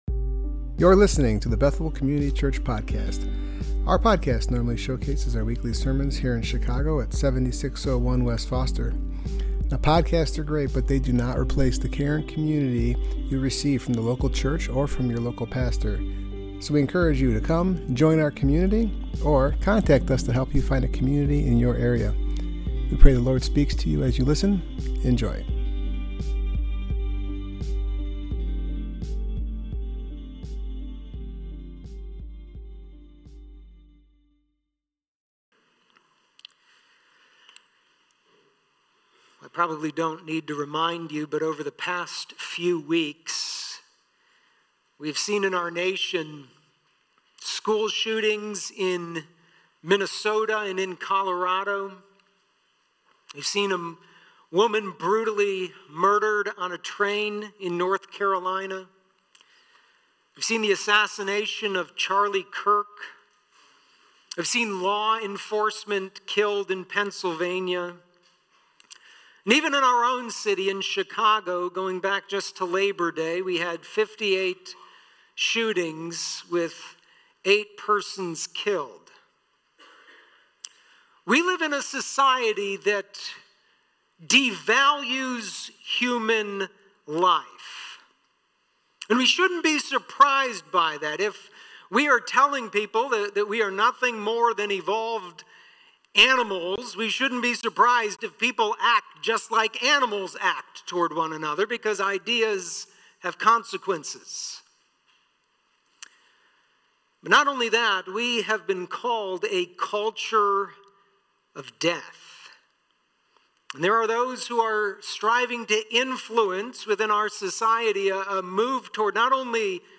Service Type: Worship Gathering Topics: anger , honoring parents , ten commandments